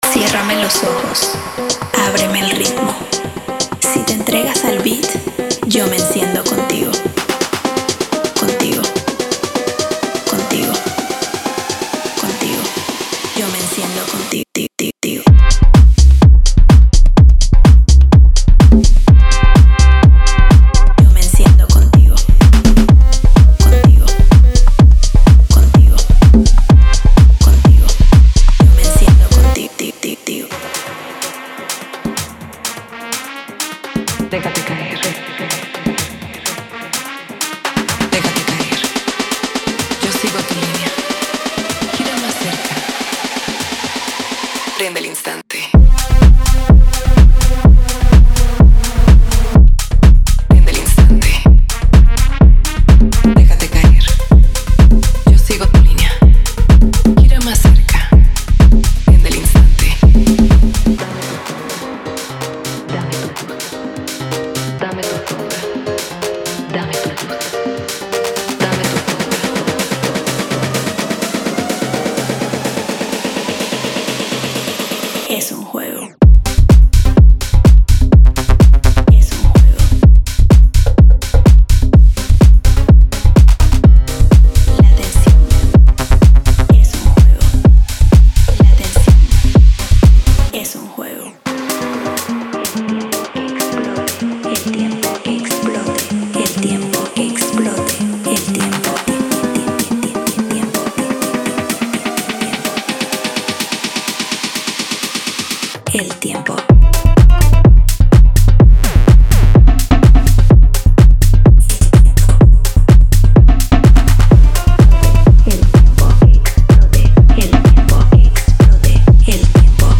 Genre:Tech House
デモサウンドはコチラ↓
Tempo/Bpm: 126